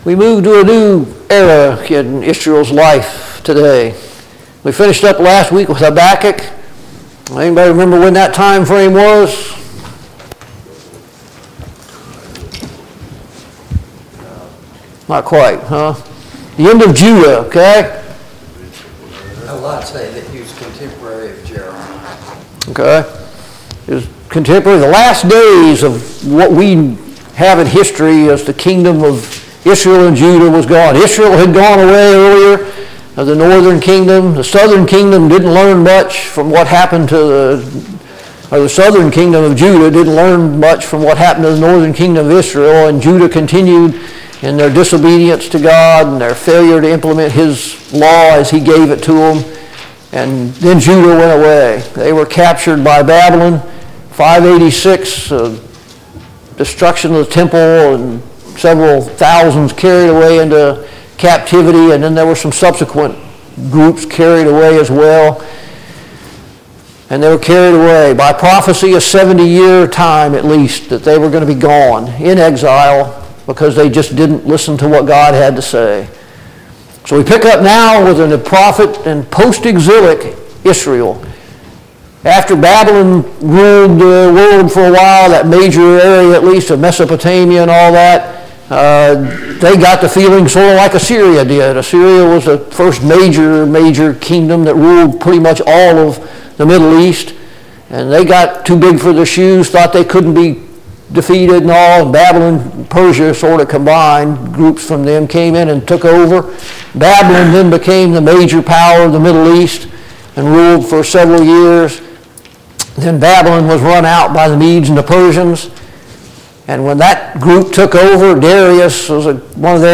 Haggai Service Type: Sunday Morning Bible Class « 21.